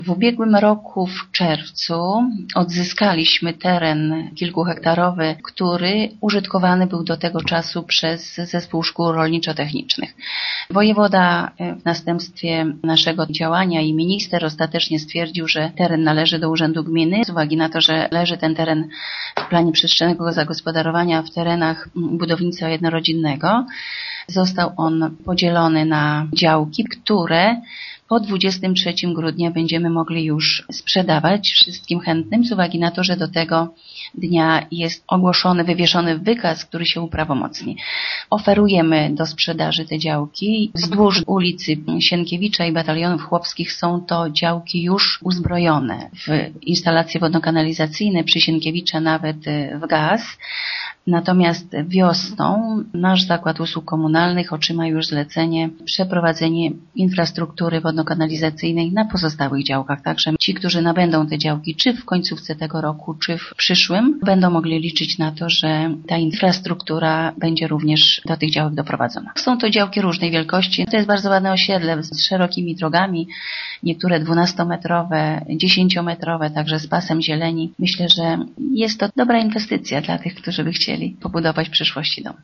„W sumie do zagospodarowania są 54 działki, wydzielone na terenie, który miasto odzyskało od powiatu zwoleńskiego” – informuje burmistrz Bogusława Jaworska: